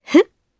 Priscilla-Vox_Happy1_jp.wav